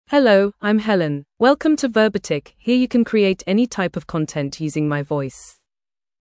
FemaleEnglish (United Kingdom)
HelenFemale English AI voice
Helen is a female AI voice for English (United Kingdom).
Voice sample
Female
Helen delivers clear pronunciation with authentic United Kingdom English intonation, making your content sound professionally produced.